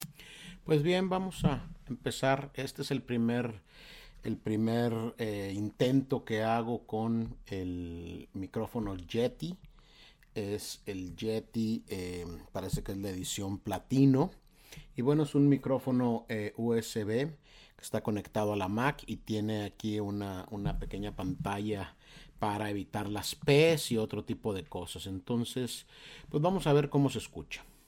Yeti microphone test 1